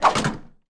Menu Statsscreen Flipout Sound Effect
menu-statsscreen-flipout.mp3